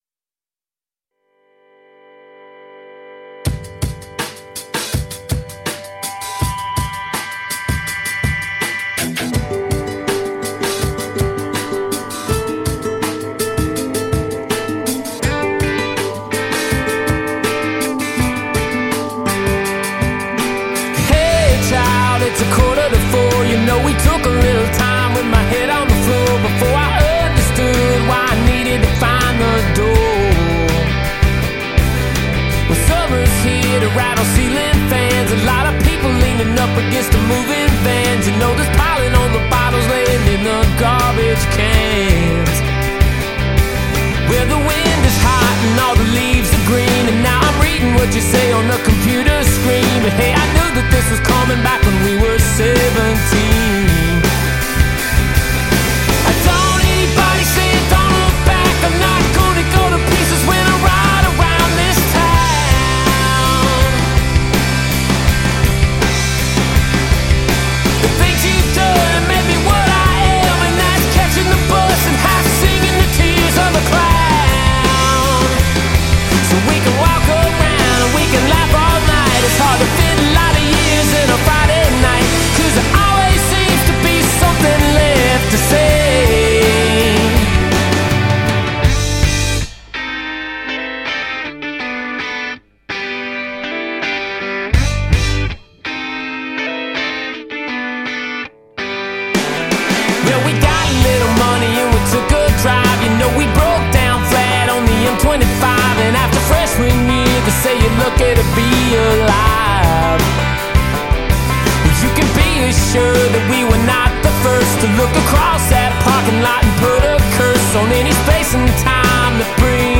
alt country band
keyboards
drums